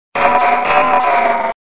Archivo:Grito de Cradily.ogg